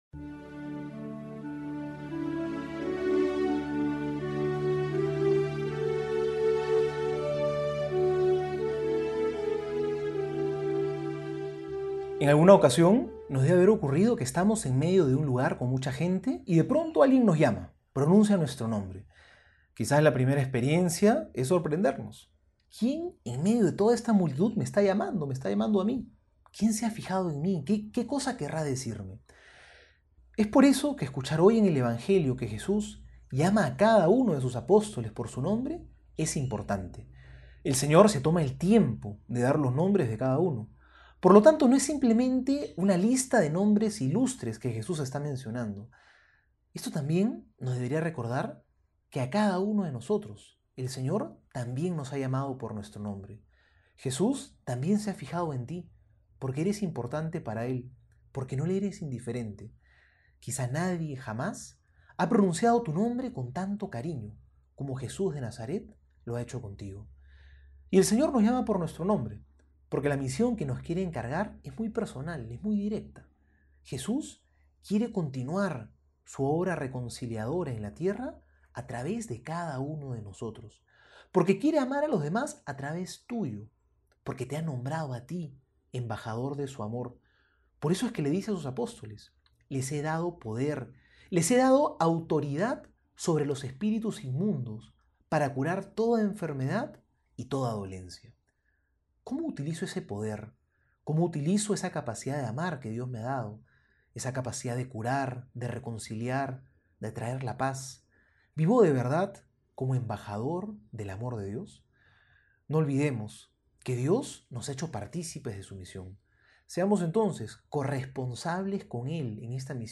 Homilía para hoy:
Miercoles Homilia Mateo 10 1-7.mp3